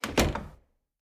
Door Close 1.ogg